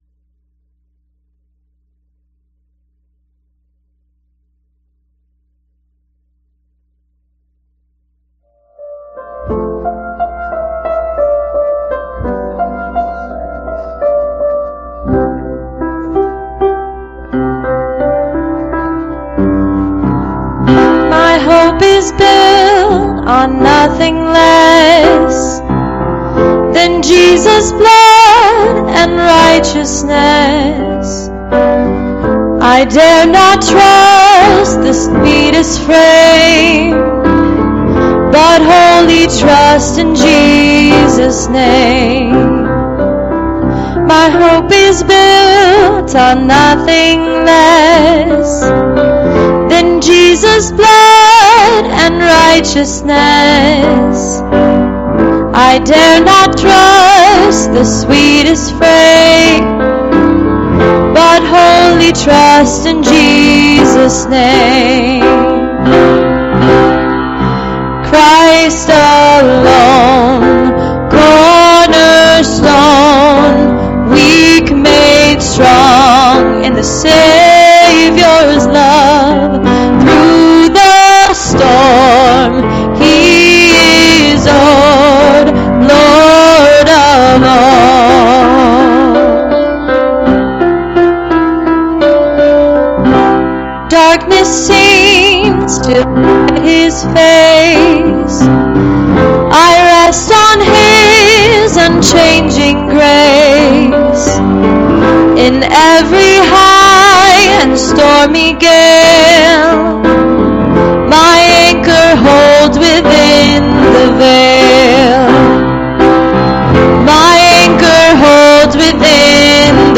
Please, click on the arrow to hear this week's service.